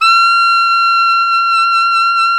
Index of /90_sSampleCDs/Roland L-CDX-03 Disk 1/SAX_Alto Tube/SAX_Alto mp Tube
SAX ALTOMP0R.wav